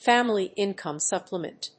アクセントfámily íncome sùpplement